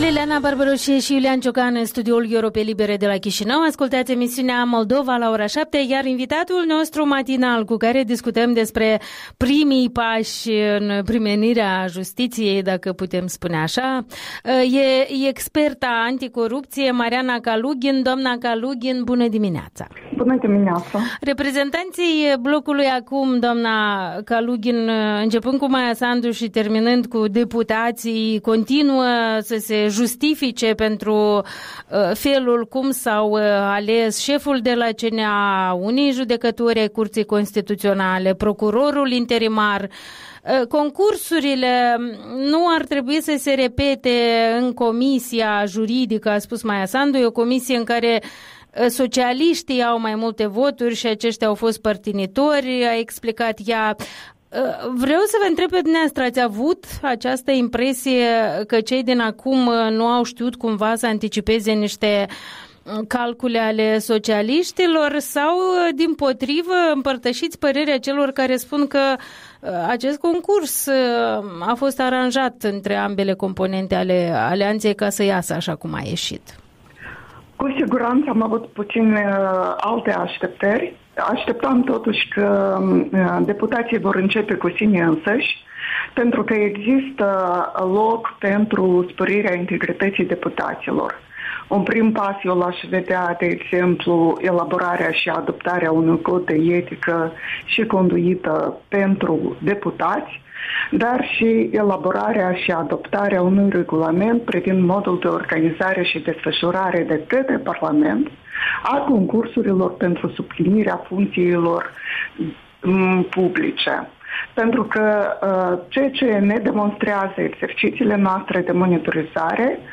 Interviul dimineții cu experta în probleme de combatere a corupției.